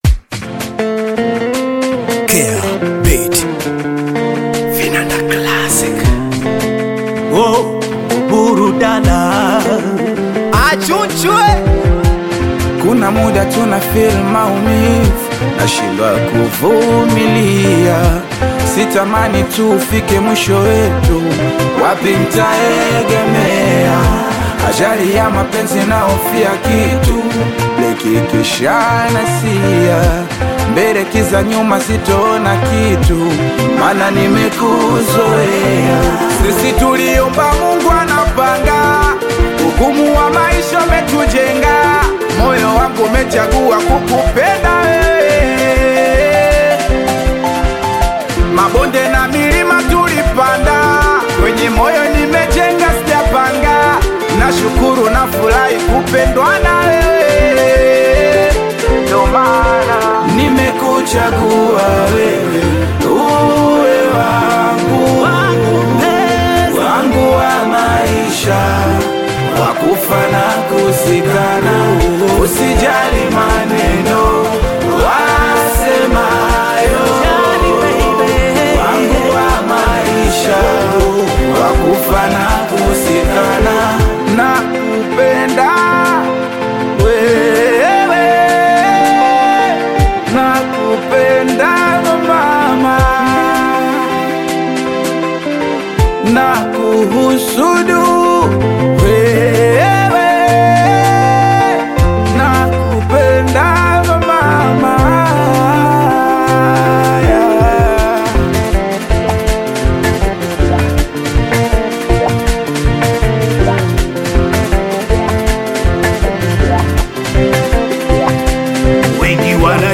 Singeli ya kisasa